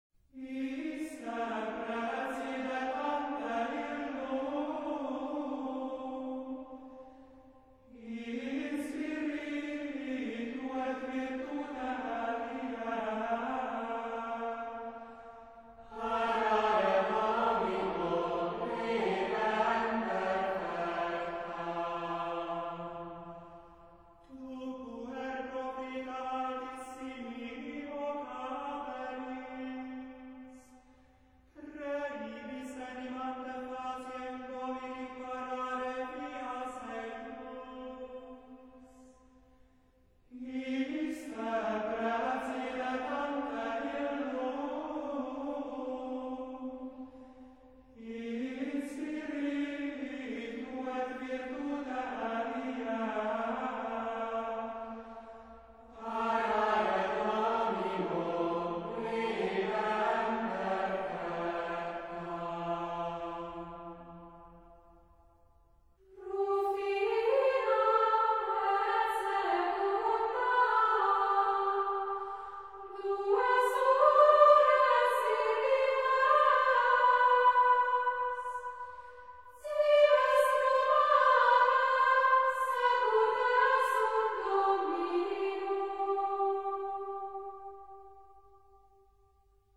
Schola Hungarica – A Pilgrimage to Rome (Old-Roman Liturgical chants)